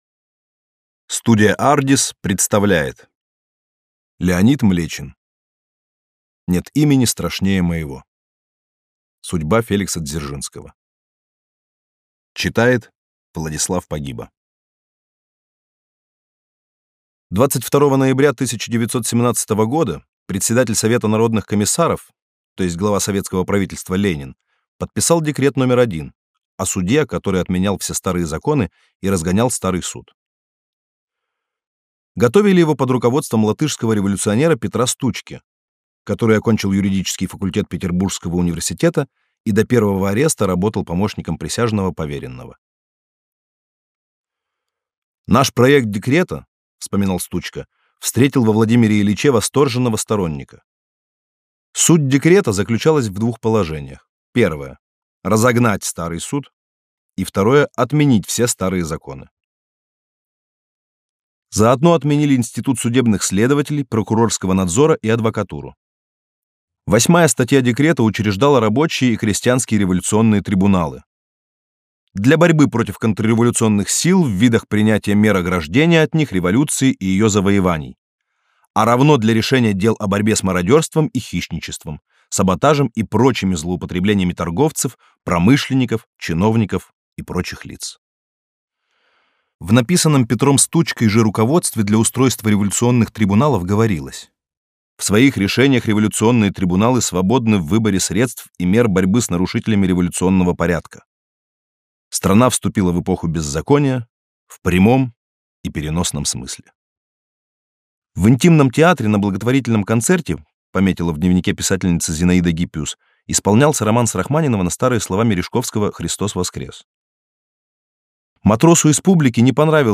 Аудиокнига Дзержинский | Библиотека аудиокниг